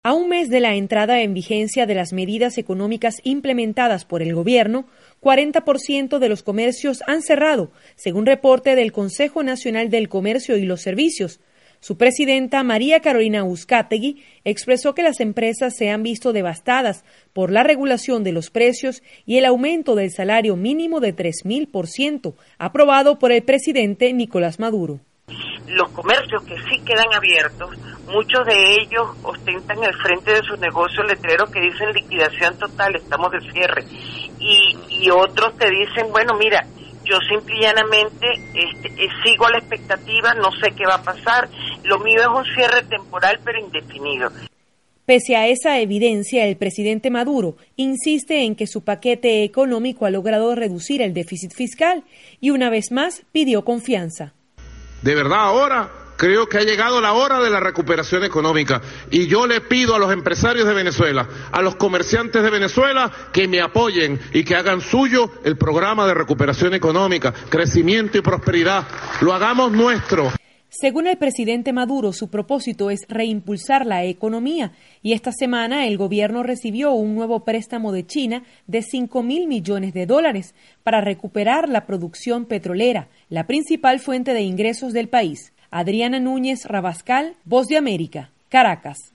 VOA: Informe de Venezuela